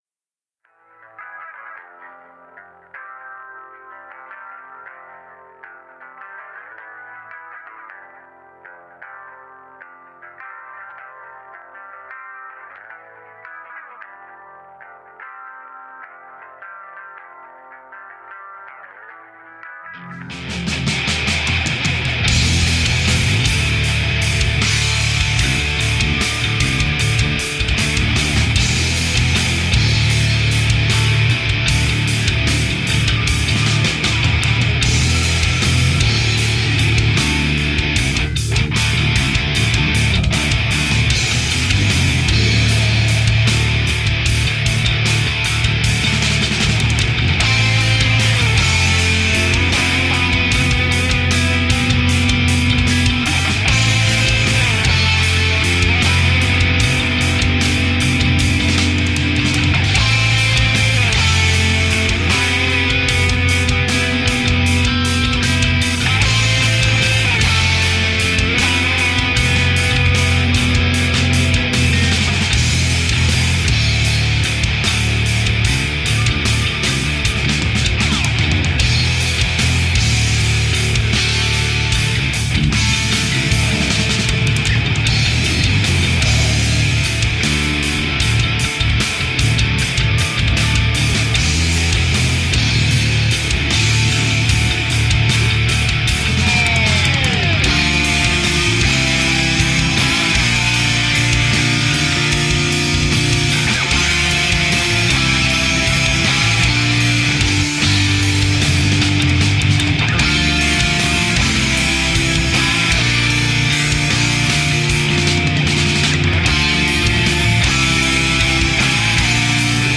A solid hybrid sound of instrumental rock.
Tagged as: Hard Rock, Rock, Metal, Instrumental